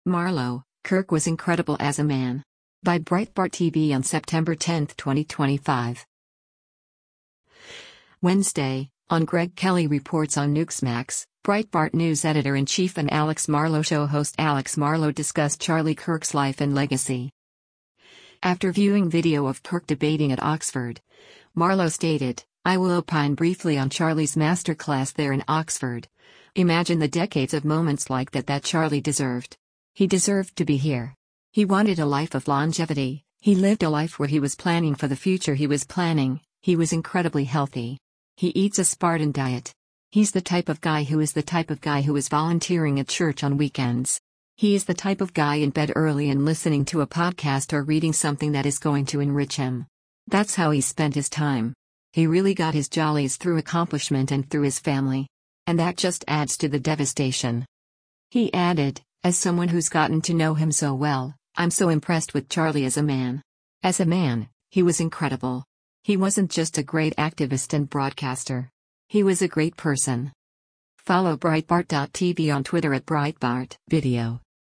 Wednesday, on “Greg Kelly Reports” on Newxmax, Breitbart News Editor-in-Chief and “Alex Marlow Show” host Alex Marlow discussed Charlie Kirk’s life and legacy.